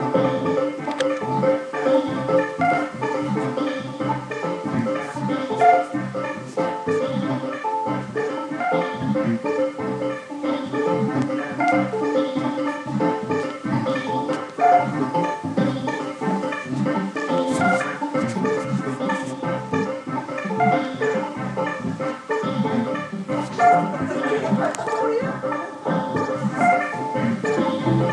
Sound sculpture, art, installation